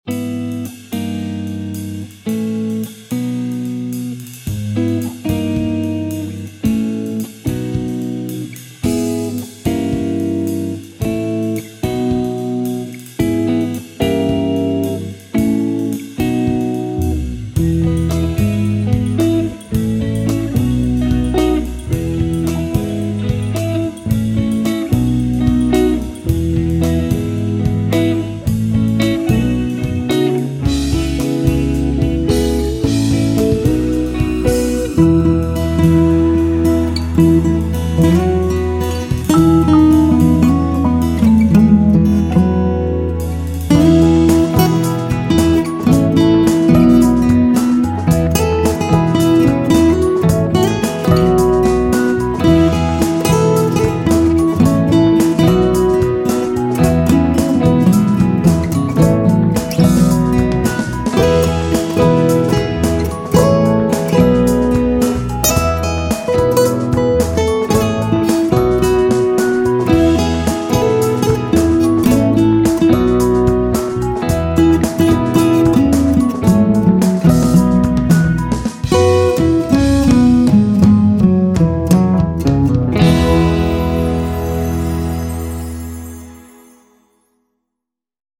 Production de musique publicitaire